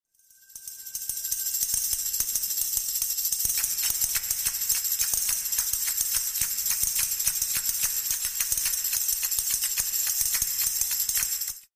Kalimba & other instruments